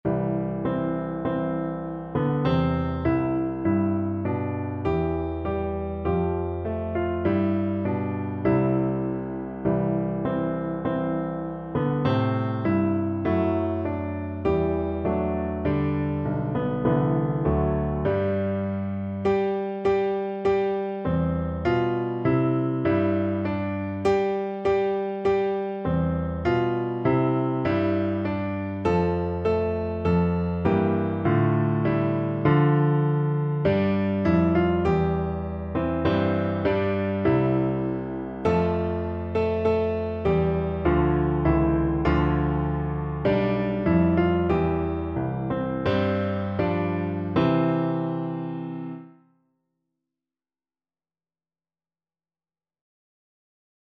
Free Sheet music for Piano Four Hands (Piano Duet)
4/4 (View more 4/4 Music)
Piano Duet  (View more Beginners Piano Duet Music)
Classical (View more Classical Piano Duet Music)